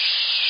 诡异的高亢刺耳的噪音
描述：在audiopaint中发出奇怪的高音尖叫声
Tag: audiopaint audiopaint 音频 怪异 油漆 发出刺耳的声音 FX 高亢 SFX 噪音